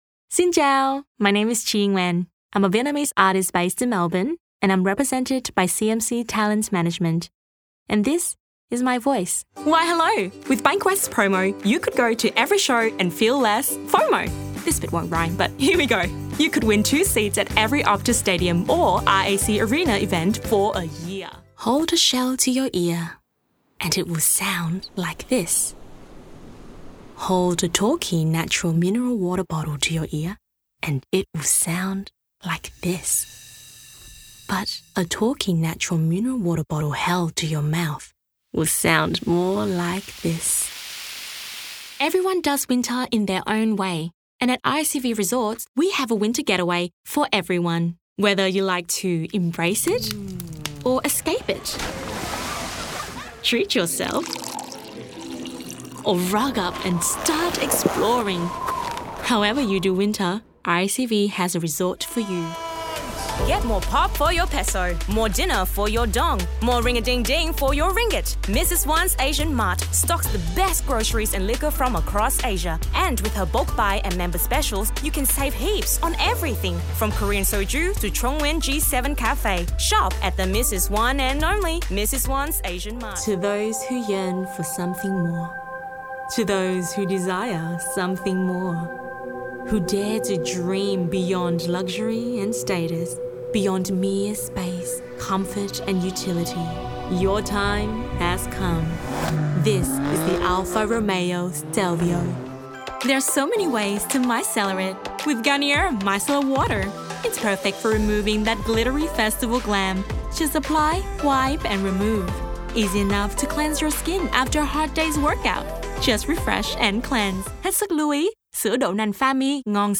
Listen here to sample iconic and emerging voices for commercial, corporate, narration , animation and character voice projects.
Female, 20's    Melbourne